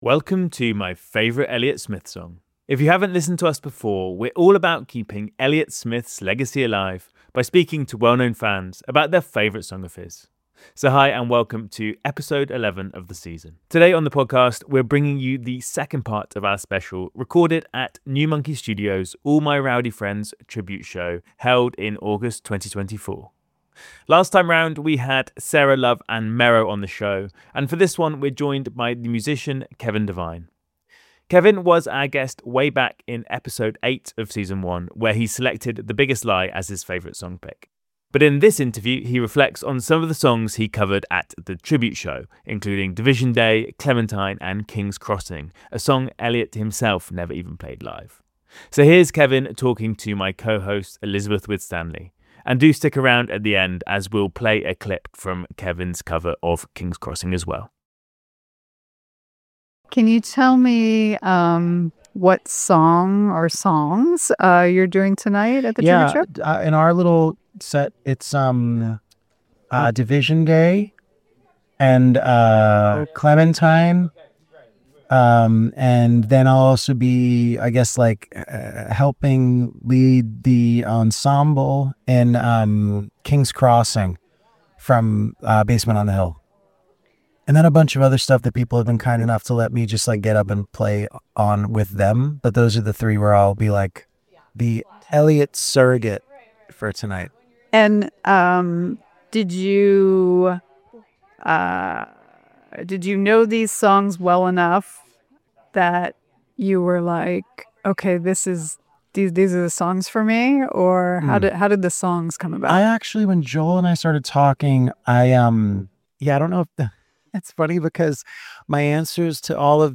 Today, we're bringing you the second part of our special recorded at New Monkey Studio's All My Rowdy Friends tribute show (held in August 2024).